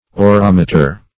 orometer - definition of orometer - synonyms, pronunciation, spelling from Free Dictionary
Search Result for " orometer" : The Collaborative International Dictionary of English v.0.48: Orometer \O*rom"e*ter\, n. [Gr.